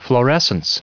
Prononciation du mot florescence en anglais (fichier audio)
Prononciation du mot : florescence